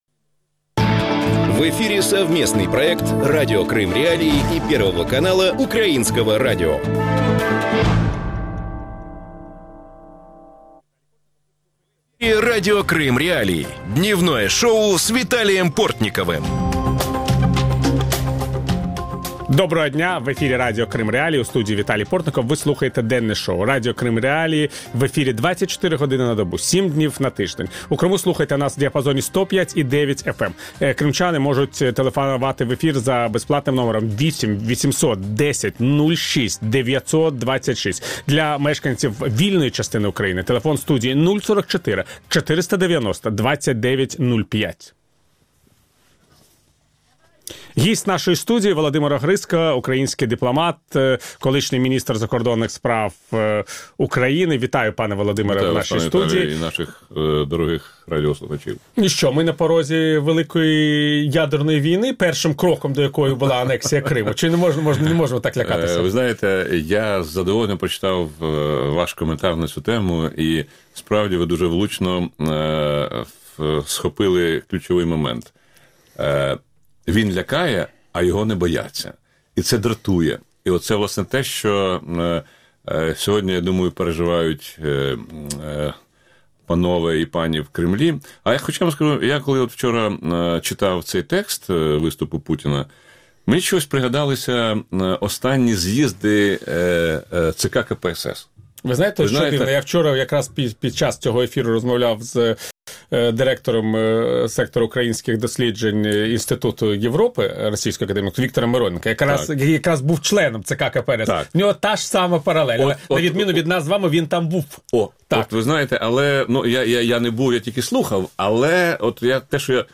Что говорят западные политики об аннексии Крыма? Остается ли тема российской агрессии против Украины в повестке дня Европейского Союза? Чем международное сообщество может помочь Украине в вопросе деоккупации Крыма и отдельных территорий Донецкой и Луганской областей? Гость студии – украинский дипломат, бывший глава Министерства иностранных дел Украины Владимир Огрызко.